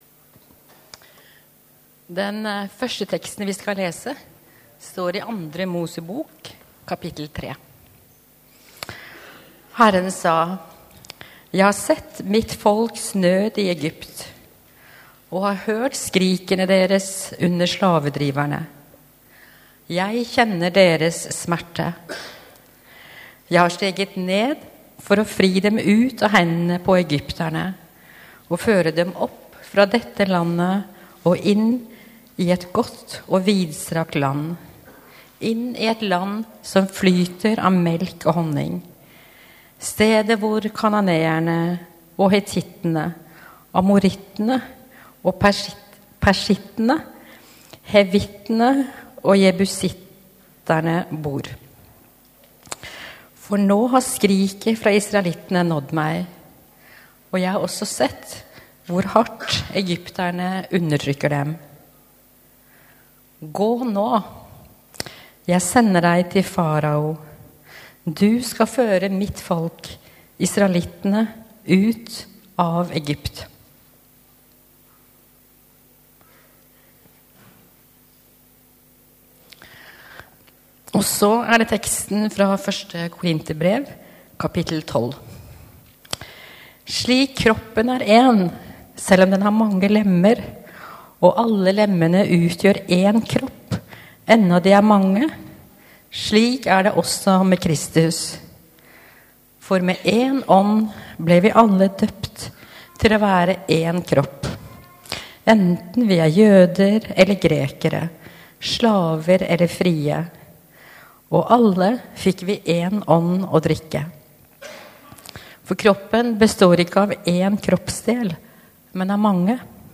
Gudstjeneste 12. november 2021, Immanuel - de forfulgtes søndag | Storsalen